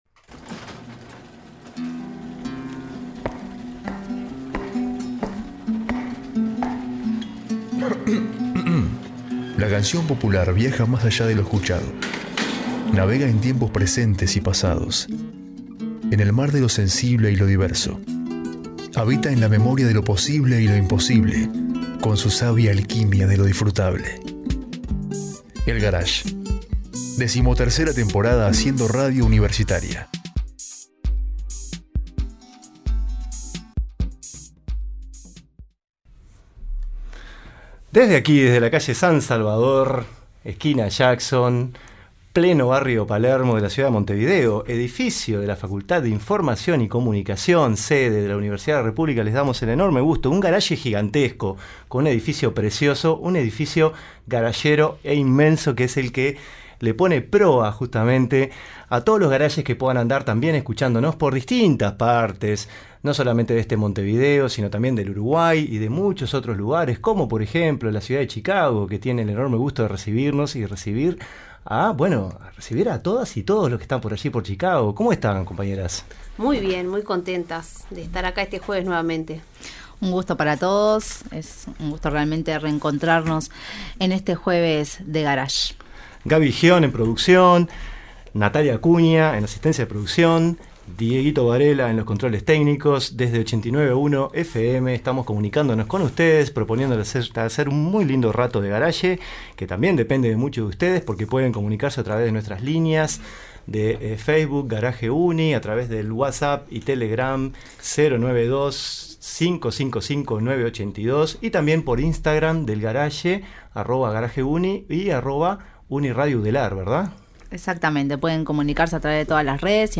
El Garage recibió en estudio